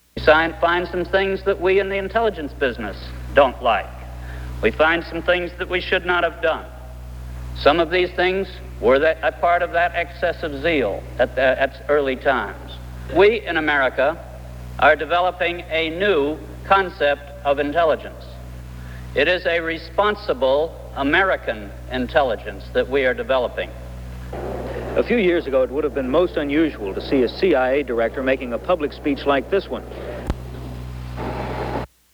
William Colby says that America is developing a new kind of intelligence gathering system in a speech before the national convention of the American Platform Association